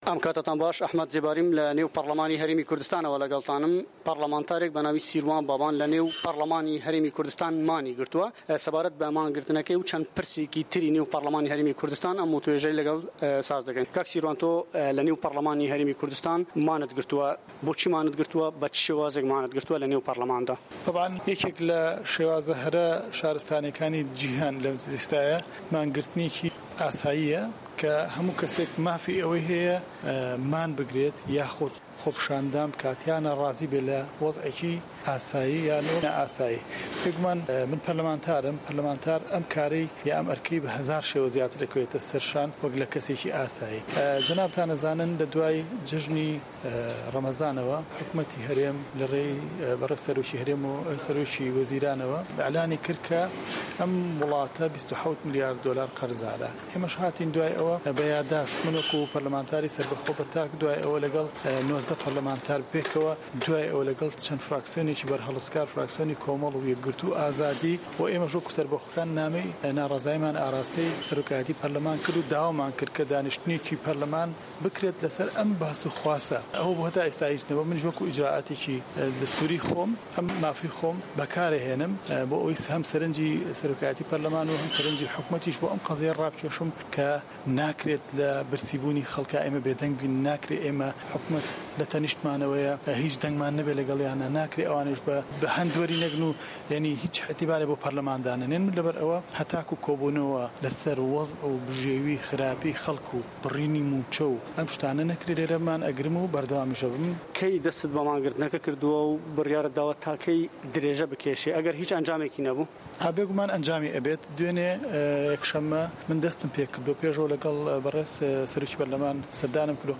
وتووێژ لەگەڵ سیروان بابان